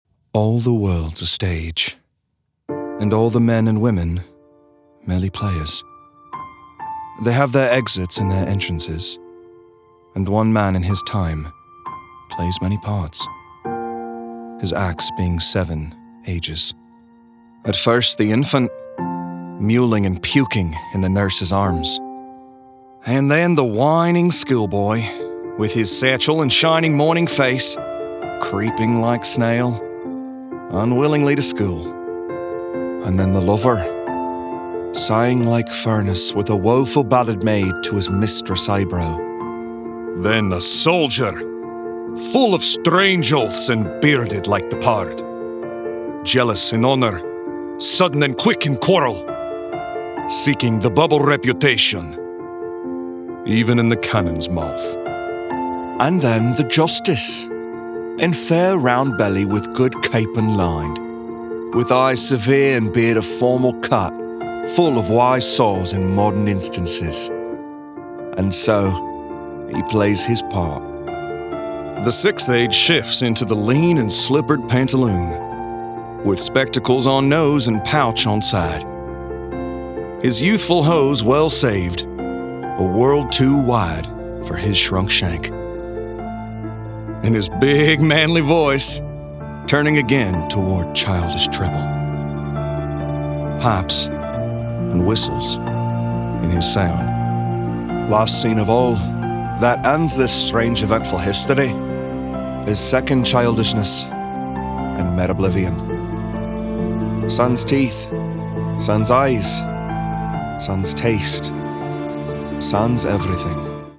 Teenager, Young Adult, Adult, Mature Adult
Has Own Studio
NARRATION 😎